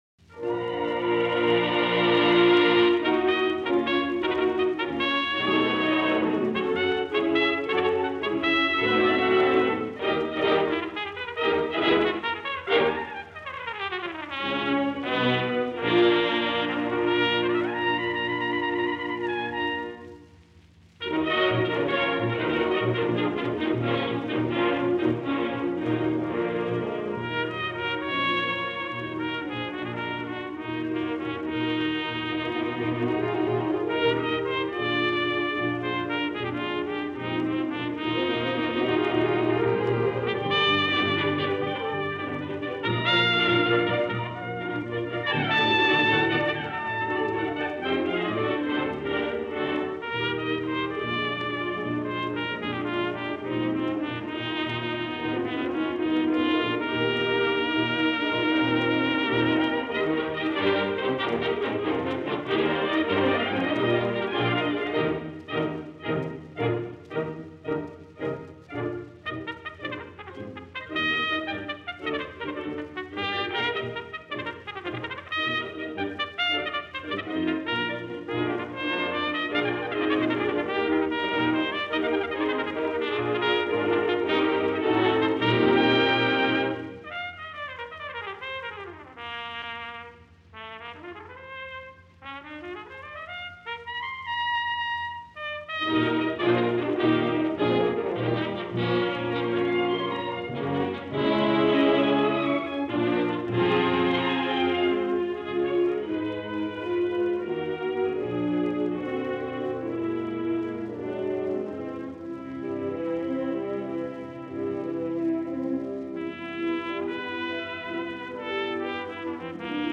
cornet